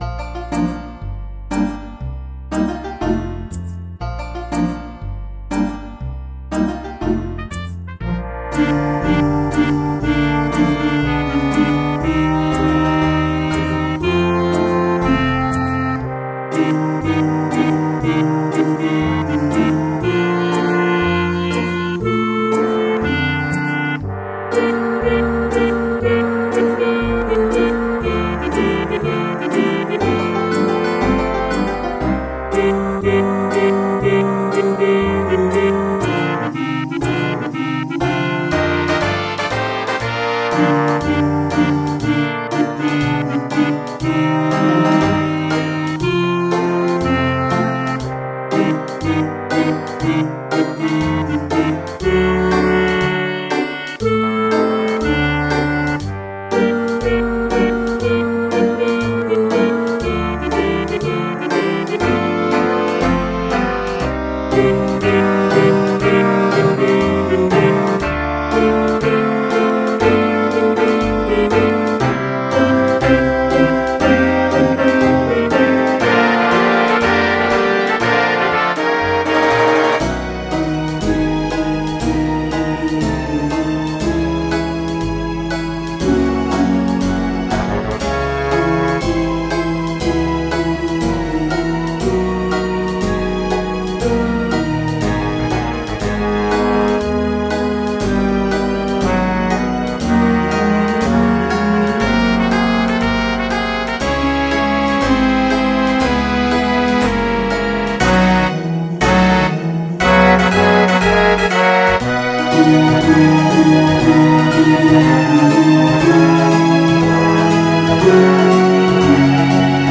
MIDI 294 KB MP3 (Converted) 6.06 MB MIDI-XML Sheet Music
- Big Band with Chorus Orchestration available